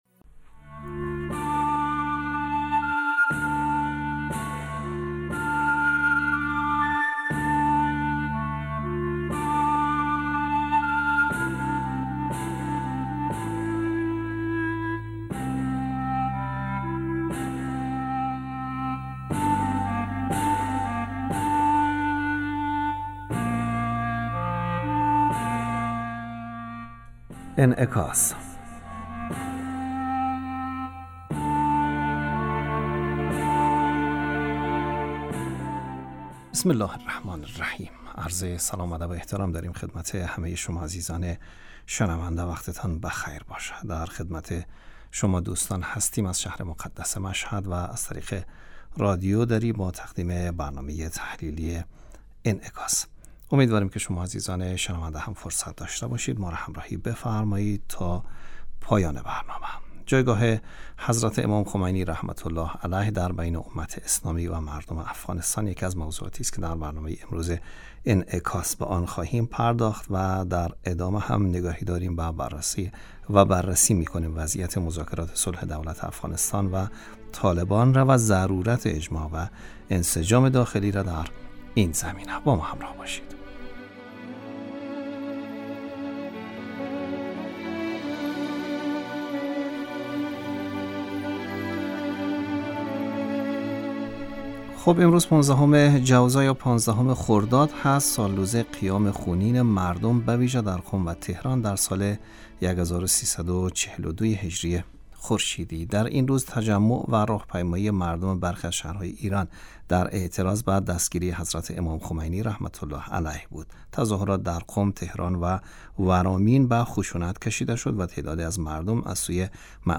برنامه انعکاس به مدت 30 دقیقه هر روز در ساعت 12:05 ظهر (به وقت افغانستان) بصورت زنده پخش می شود.